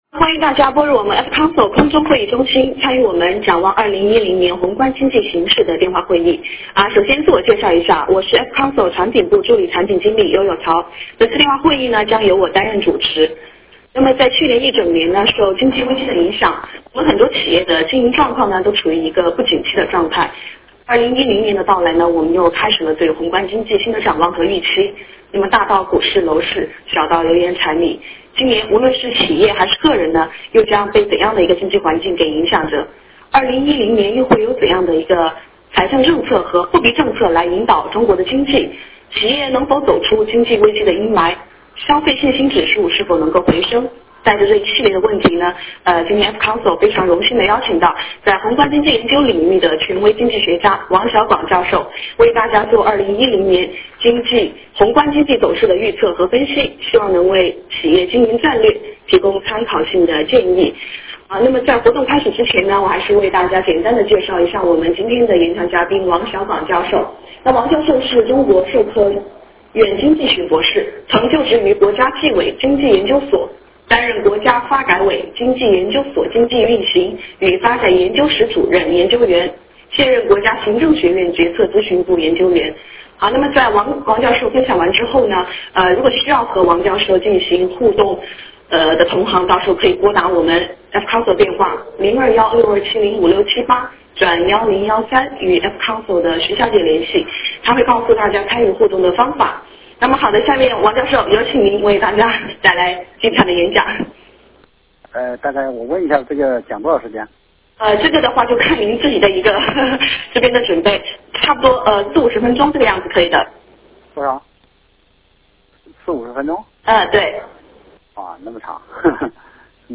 电话会议
Q&A 互动环节